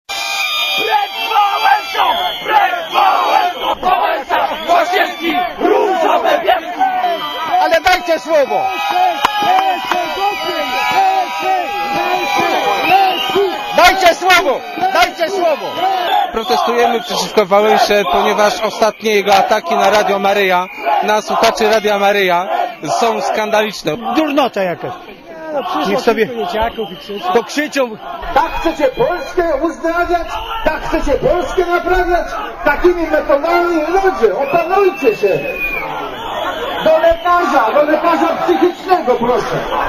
Komentarz audio
walesagwizdy.mp3